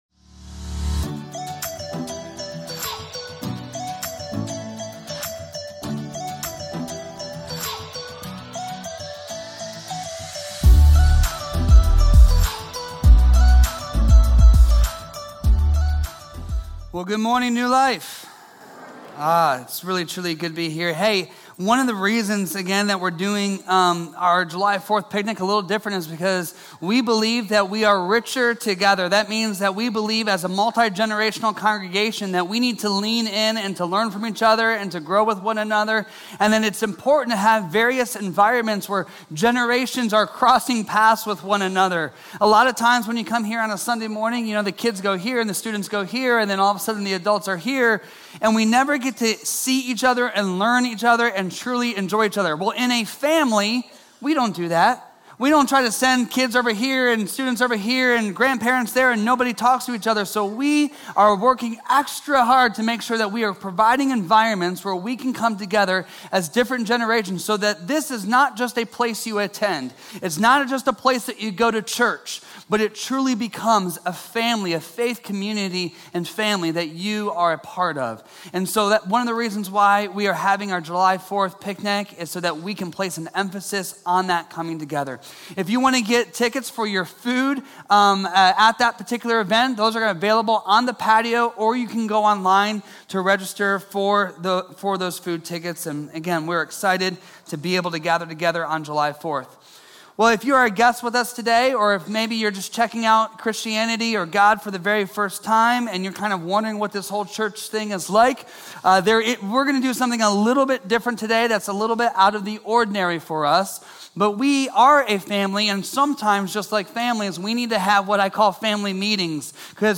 A message from the series "Galatians."